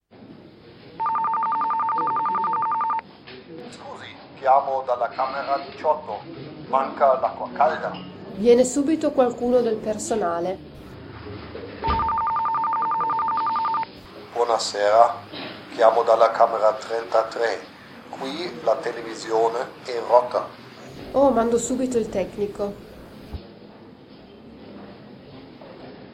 In jeder Übung sind Muttersprachler zu hören, die sich mit in einer Fremdsprache sprechenden Personen unterhalten.